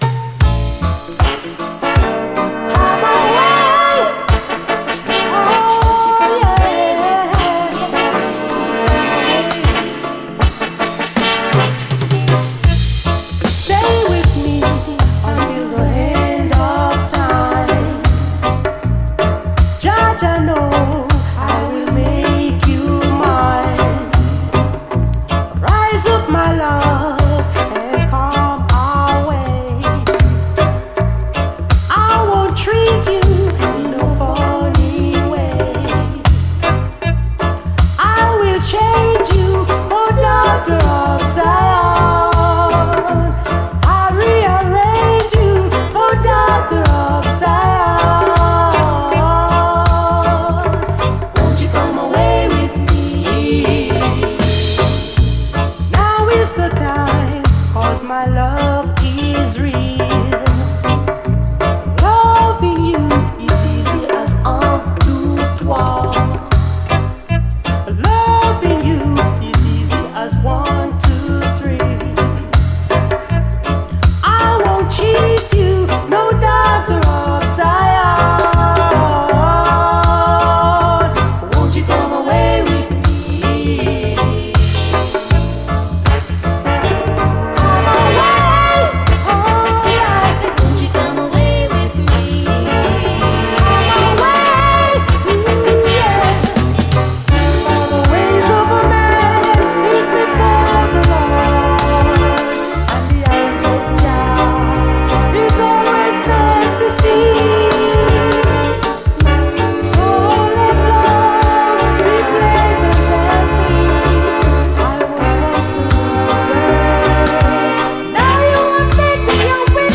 REGGAE, 7INCH
涼しげなハーモニーと残響のドリーミーUKラヴァーズ！